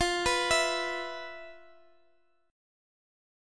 E5/F Chord